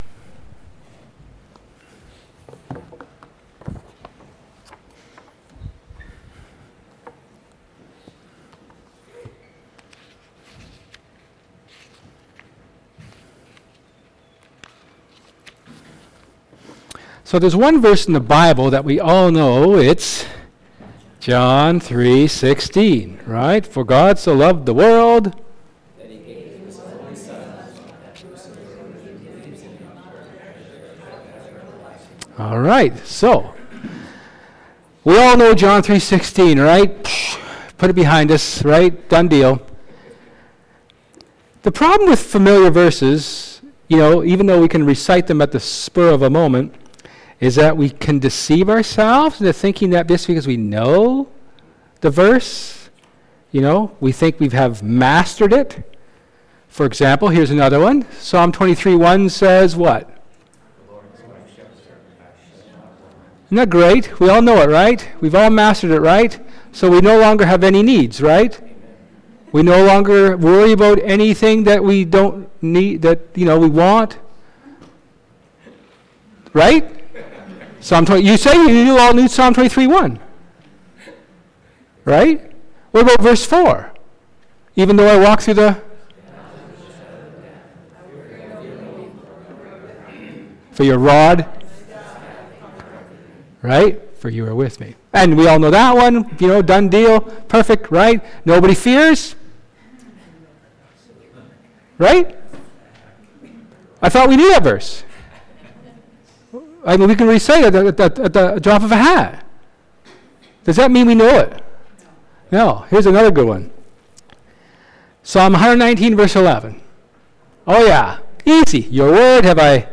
Luke 2:8-20 Service Type: Sermon